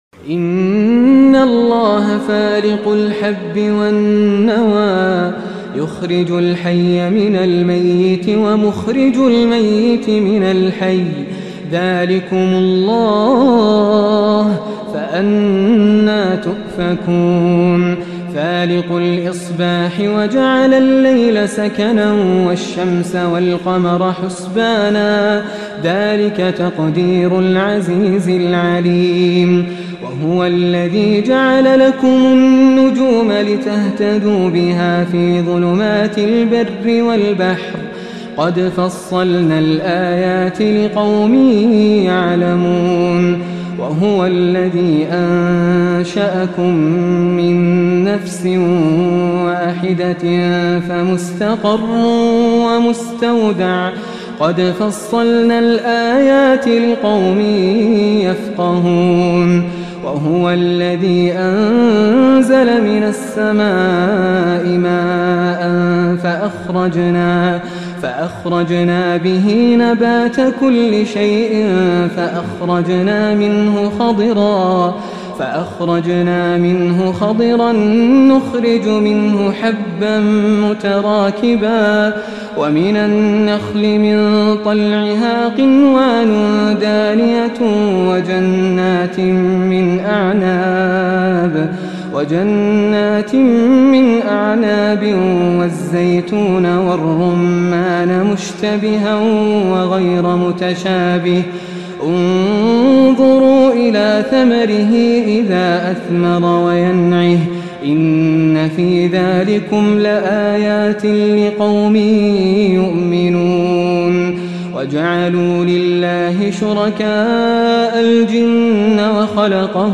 تلاوة من الأنعام